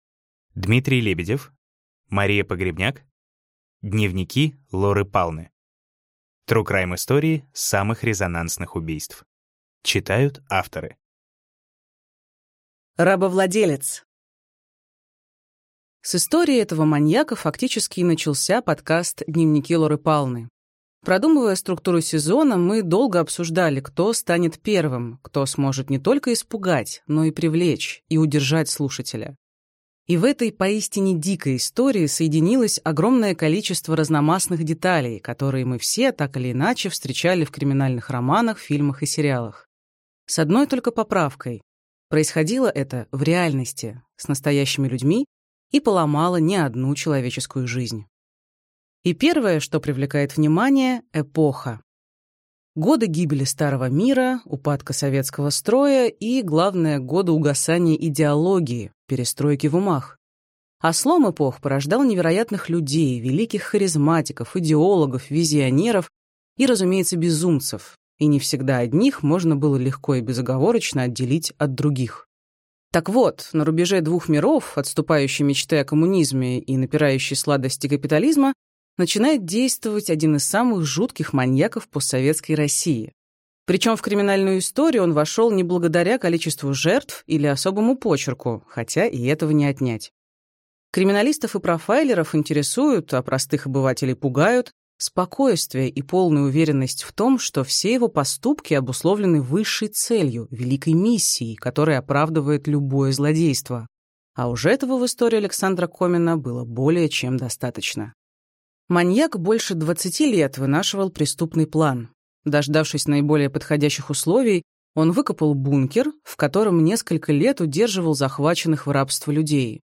Аудиокнига Дневники Лоры Палны. Тру-крайм истории самых резонансных убийств | Библиотека аудиокниг